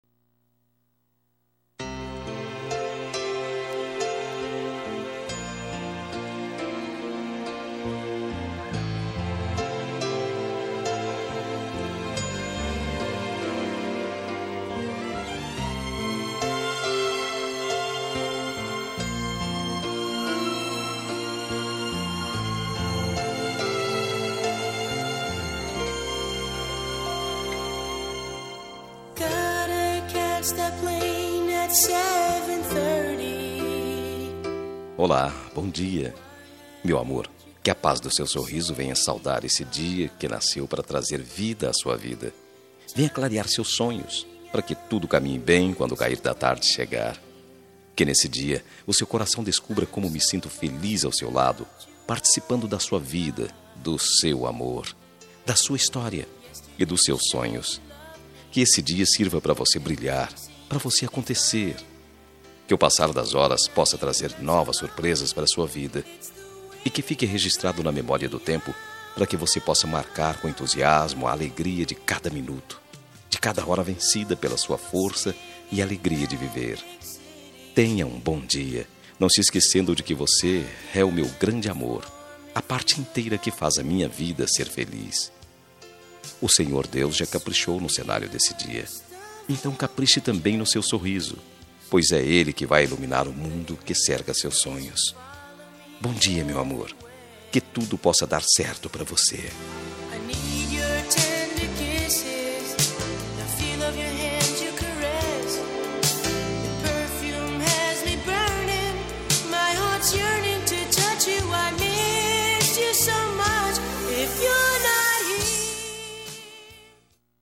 Telemensagem de Bom dia – Voz Masculina – Cód: 6325 – Romântica